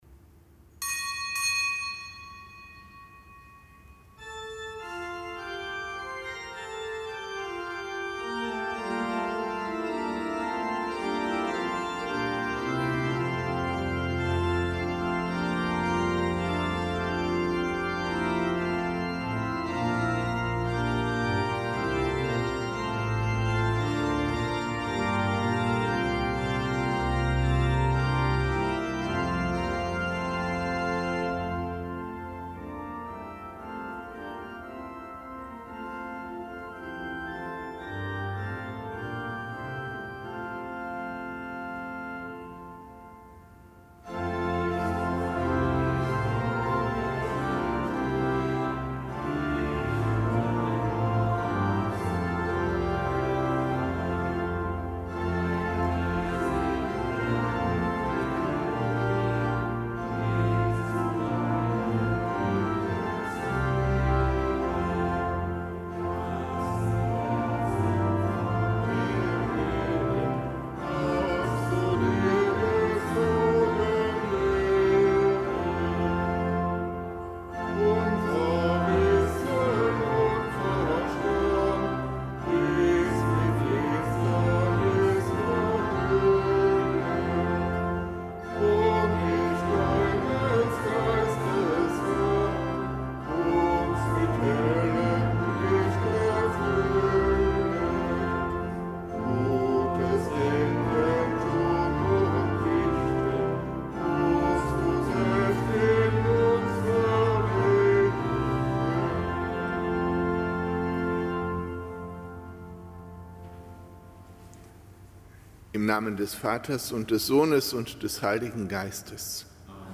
Kapitelsmesse am Donnerstag der sechzehnten Woche im Jahreskreis
Kapitelsmesse aus dem Kölner Dom am Donnerstag der sechzehnten Woche im Jahreskreis, dem nicht gebotenen Gedenktag des heiligen Christophorus, Märtyrer in Kleinasien (RK); und des heiligen Scharbel